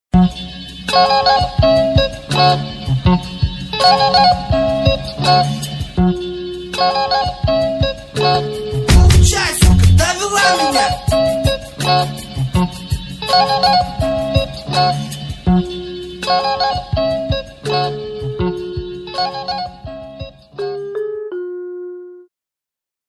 Поп музыка, Eurodance